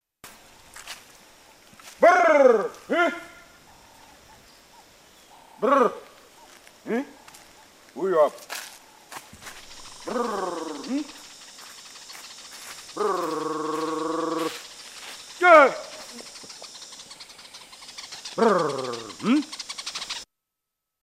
Yao honeyhunter calling a honeyguide